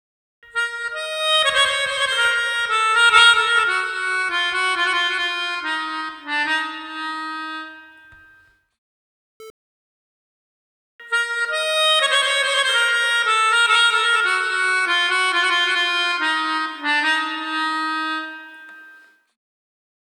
However, I was inspired by some bosnian or balkan tunes and I have decided to post this music here.
I’m not familiar with that style of music, so I may be barking up the wrong tree here, but IMO that would benefit from dynamic-range-compression: so that there is less variation in the volume of the notes.